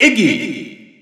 Announcer pronouncing Iggy in French.
Iggy_French_Announcer_SSBU.wav